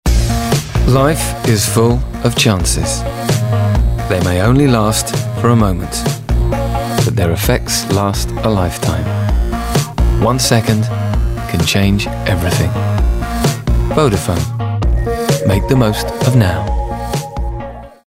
Neutral relaxed RP.
• Male
• London
• Standard English R P
Vodaphone Commercial